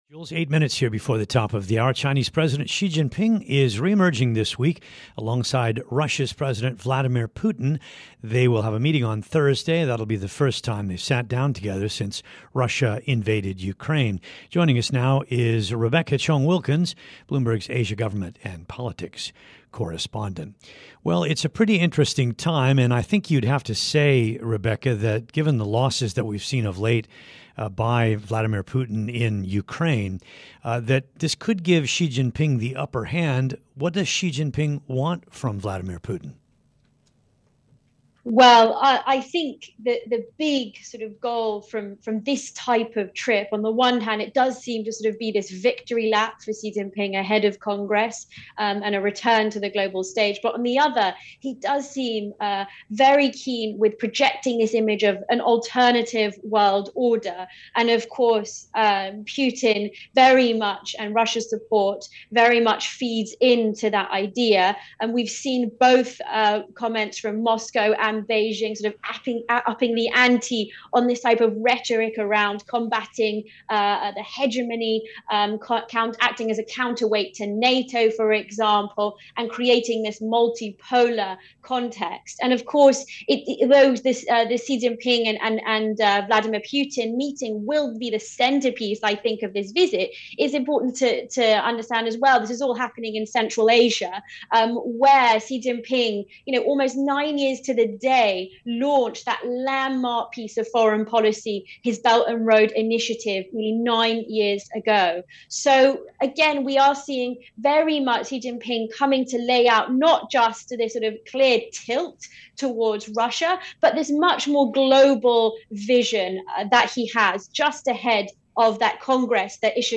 on Bloomberg Radio